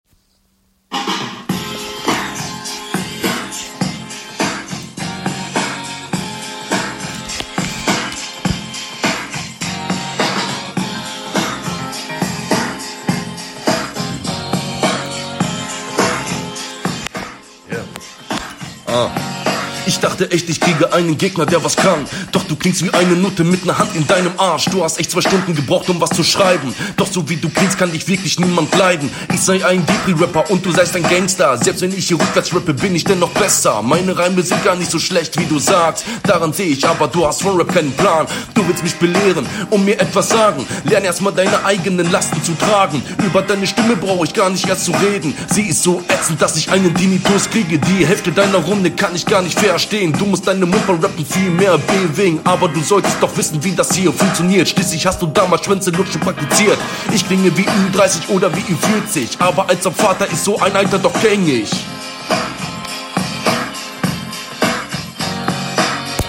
Soundqualität ist echt belastend für die Ohren, klingt so als hättest du mit dem Handy …
Leider nicht das gelbe vom Ei. Der Flow klingt Standard und ist somit auch das …
Flow: Du hast ne sehr gute Stimme, nur die Verslänge stimmt einige Male nicht ganz …